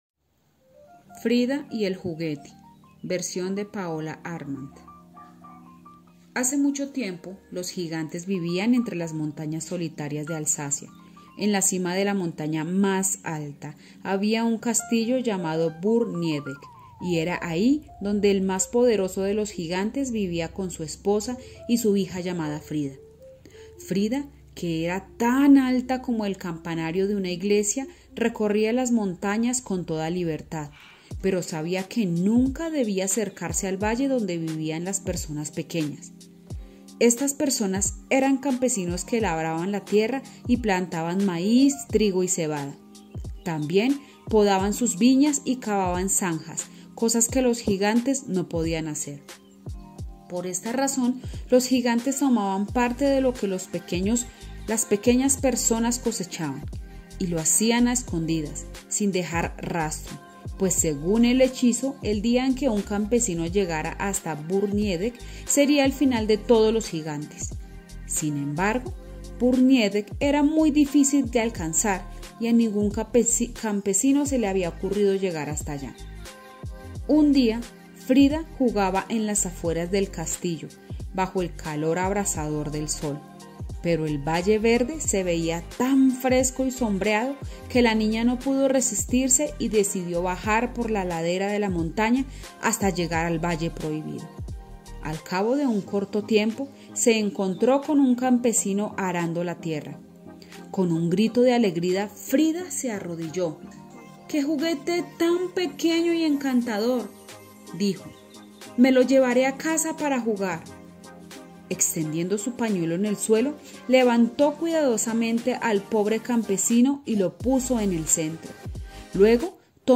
Frida_y_el_juguete_-_Audio_cuento.mp3